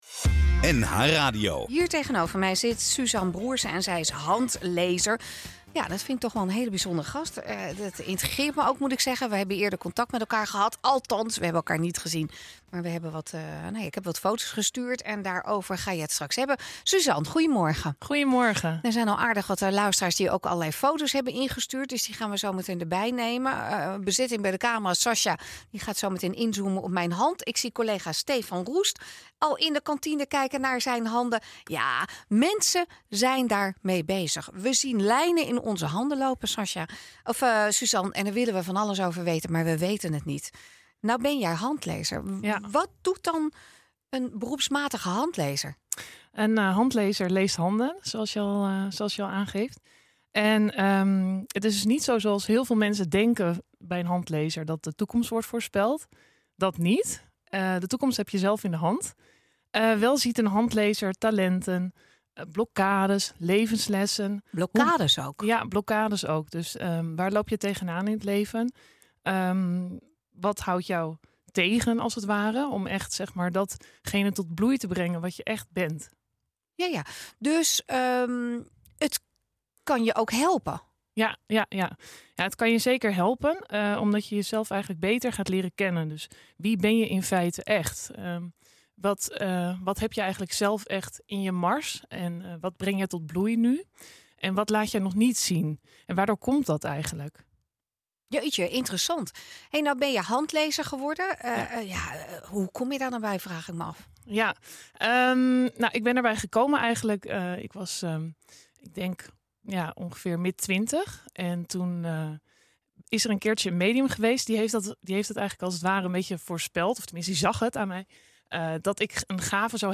Ha, best spannend, zo’n eerste keer live op de radio.